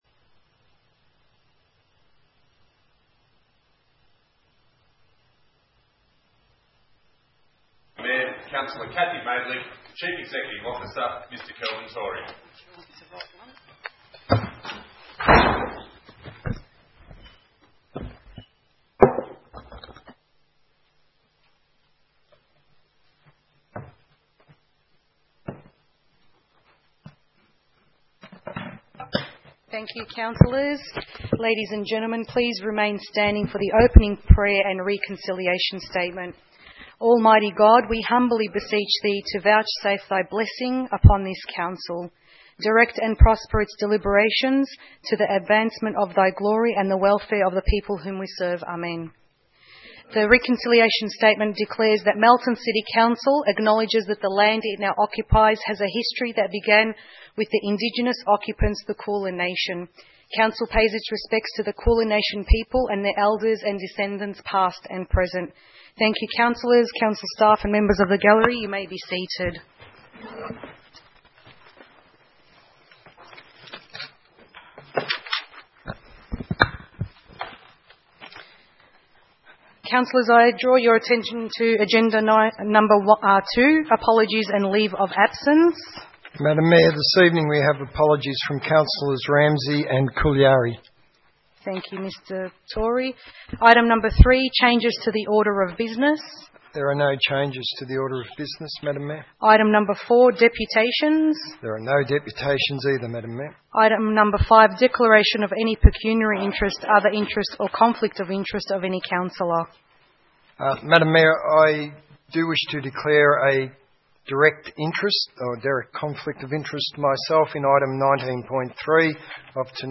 2 May 2016 - Ordinary Council Meeting